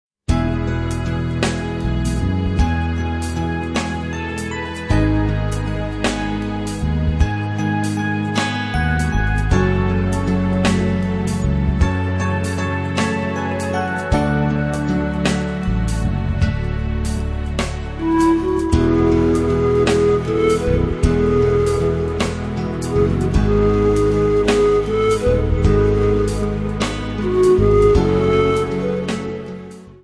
A compilation of Christmas Carols from Poland
panpipes